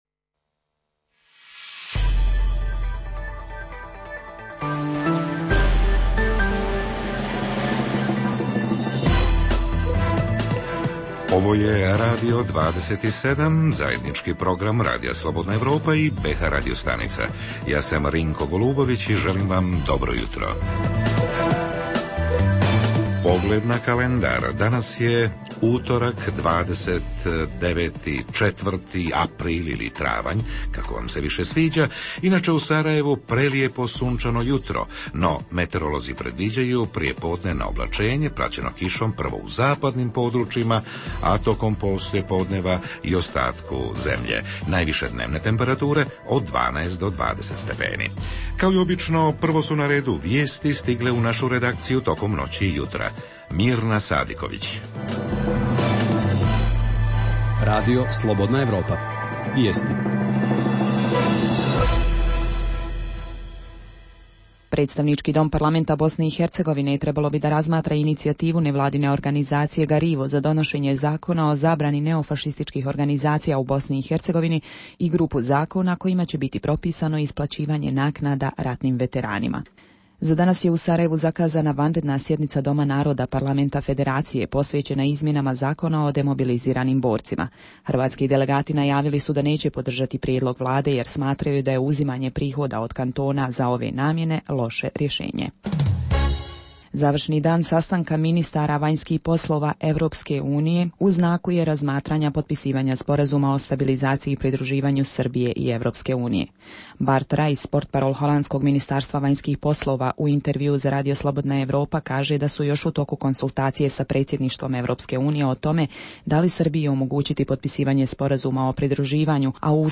Kako prehranom očuvati zdravlje i preduprijediti gripu, proljetnu malaksalost, smanjenu otpornost organizma? Čujmo savjete nutricionista i ljekara, ali i riječ građana - da li vode računa o raznolikosti svoje prehrane i kako se snalaze u ovom periodu kada su poskupjele sve osnovne životne namirnice, a cijene povrća i voća na pijacama skočile pod oblake.
Redovni sadržaji jutarnjeg programa za BiH su i vijesti i muzika.